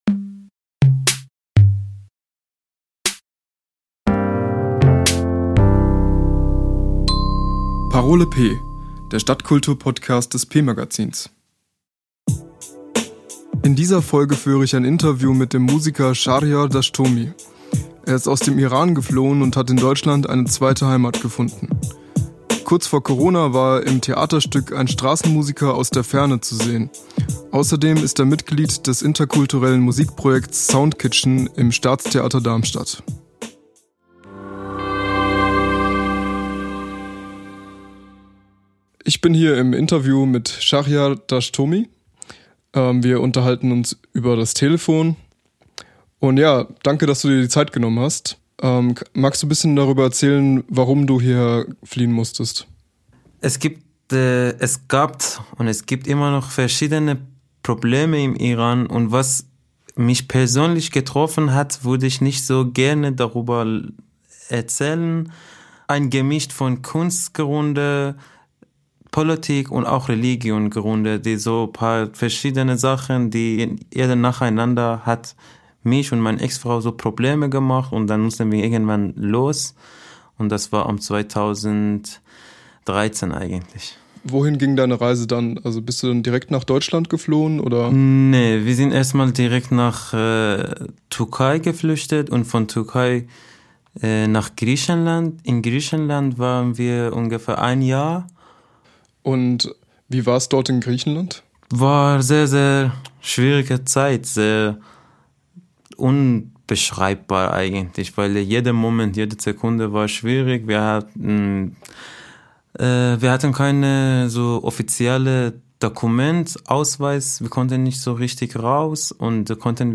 Im Interview erzählt der Songwriter von seiner Leidenschaft für Straßenmusik, dem Engagement als Schauspieler und der Flucht aus dem Iran nach Deutschland...